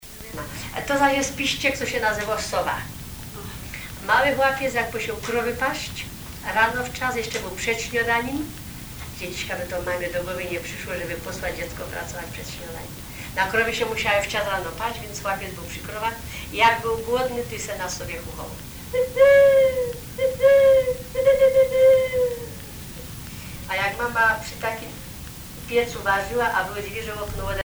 Piszczek
Pièce musicale inédite